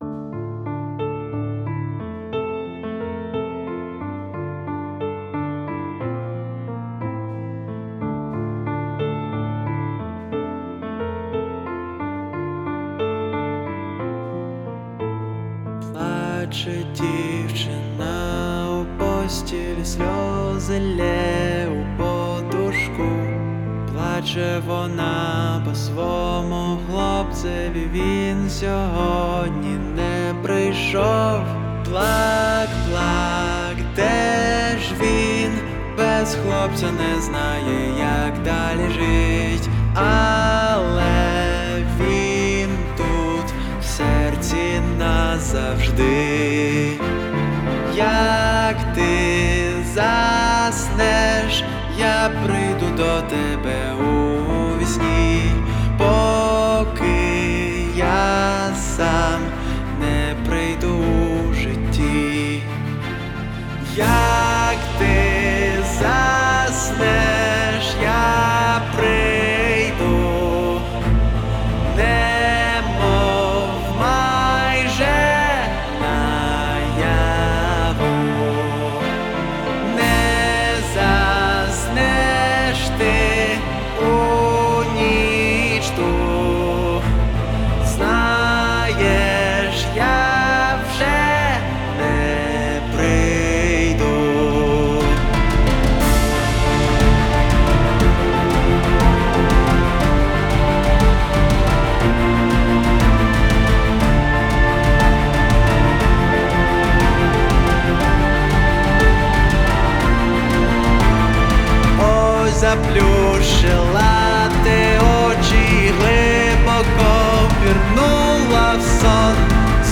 Стиль: Саундтрек